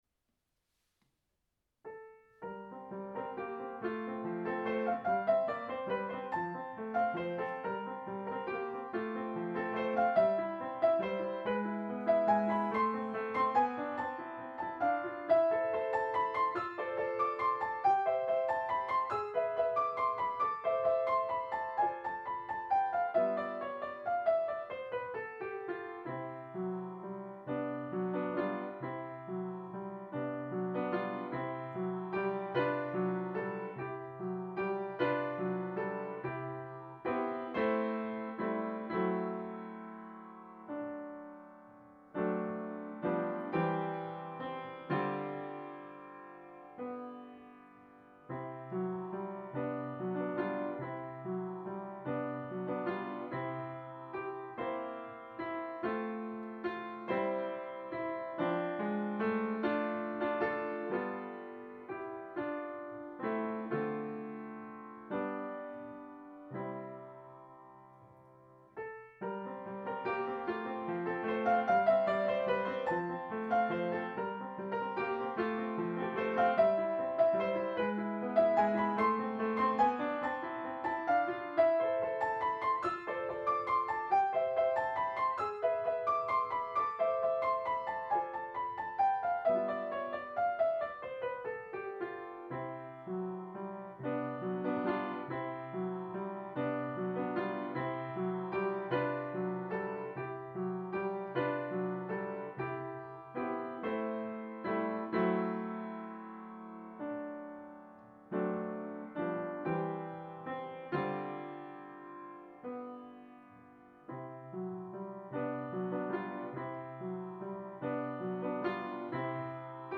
Aufnahme der Sonatine, gespielt auf meinem Trautwein Klavier von 1907 und aufgenommen mit einem Zoom H2.